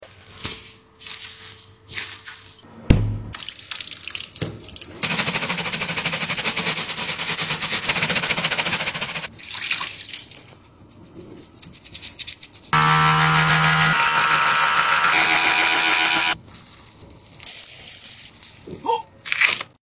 CLEANING SQUISHY ASMR🐤🐤Chicken#asmr